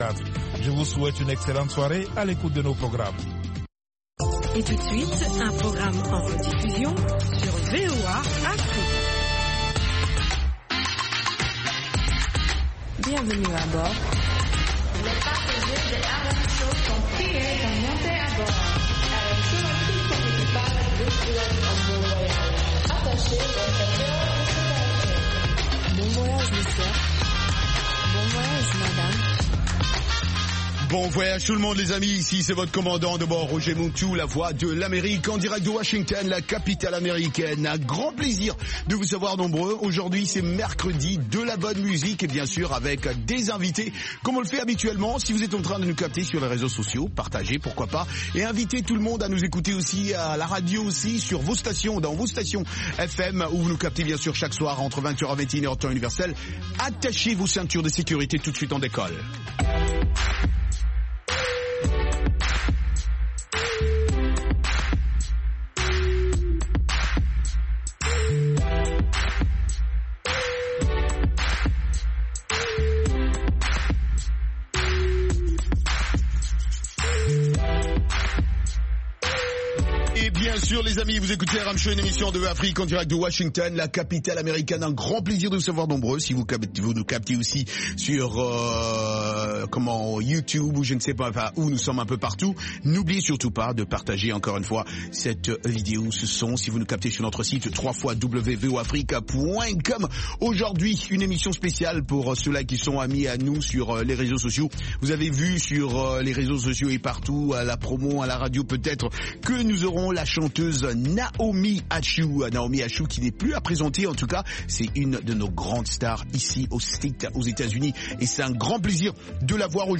Balade musicale dans le monde entier, rions un peu avec de la comédie, interviews des divers artistes